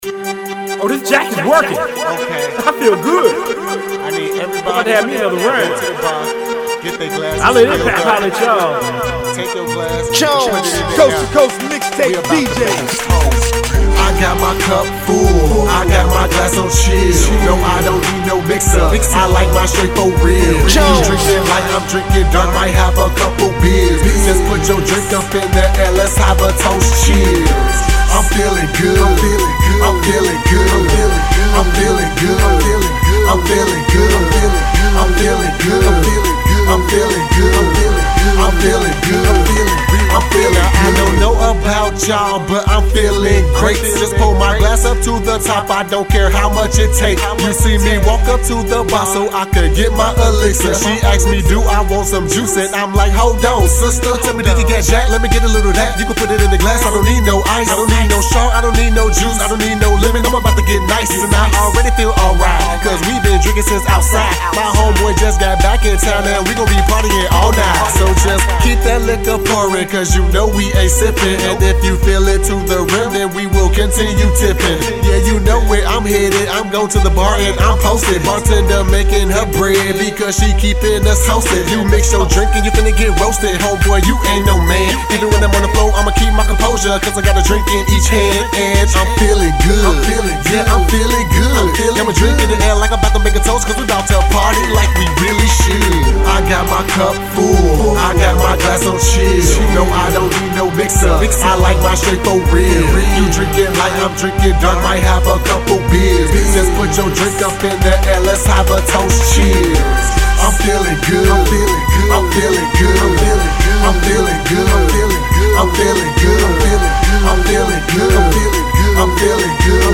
(Hip Hop/Rap)
A lil info: I made the beat using FL Studio 9. I recorded at home using Sonar 8.5 (Behringer B-2Pro mic, behringer ultrigain pre[that I upgraded to VTB1 recently]).